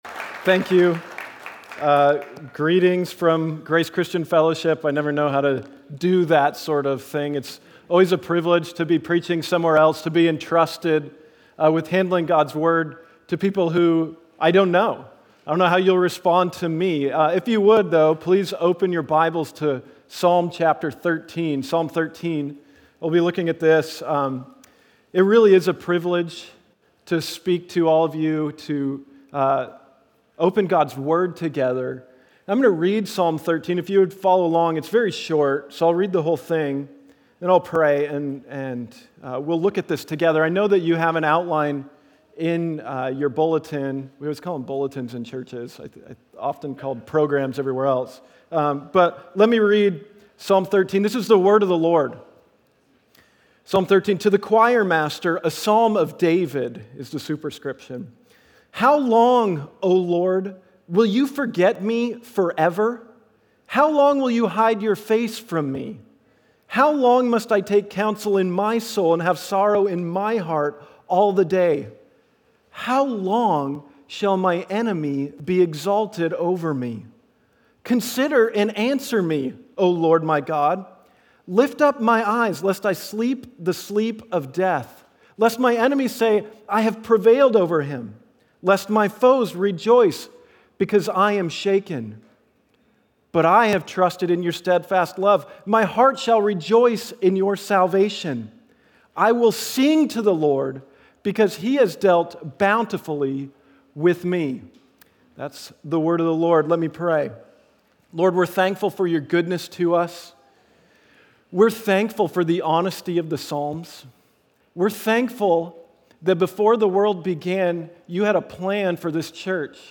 When You Are Down - Guest Speaker